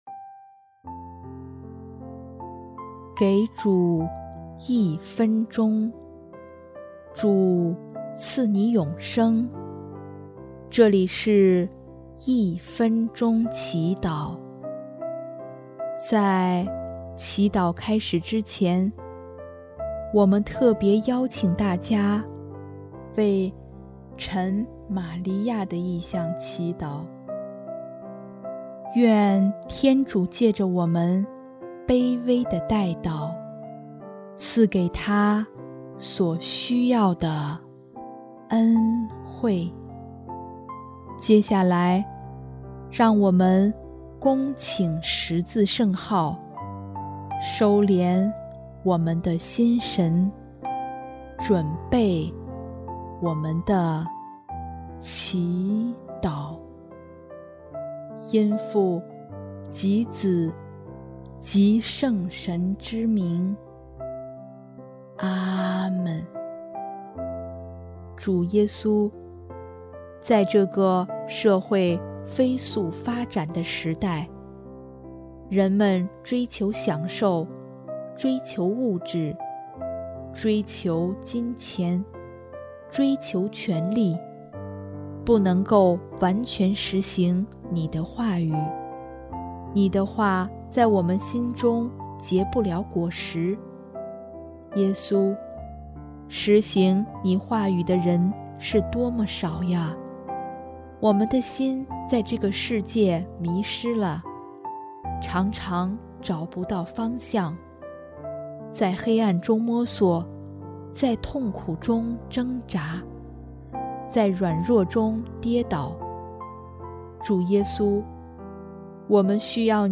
【一分钟祈祷】|9月24日 主，让我们有能力实行你的话